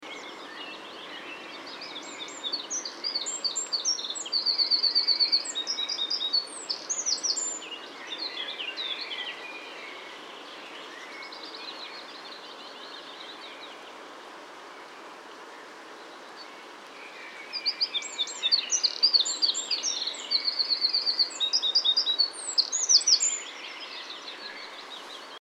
PFR03415, 090601, Winter Wren Troglodytes troglodytes, song, Bergen, Norway